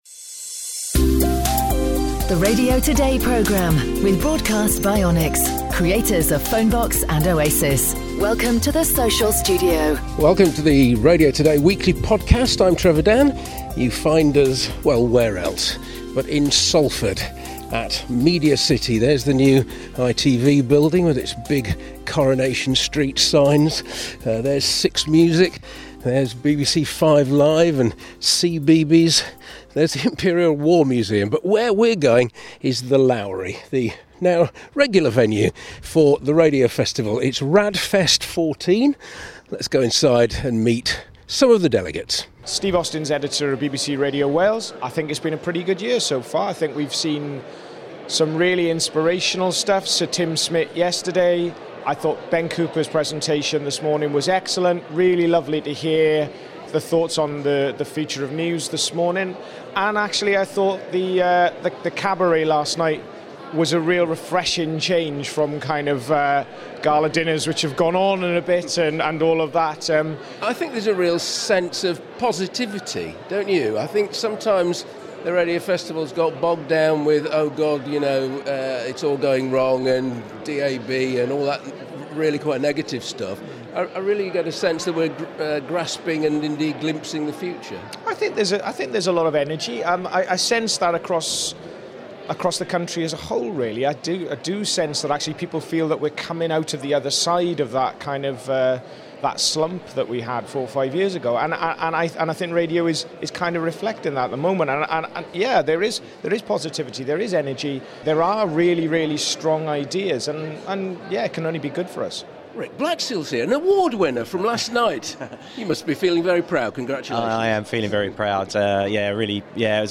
meets delegates at this year's Radio Festival in Salford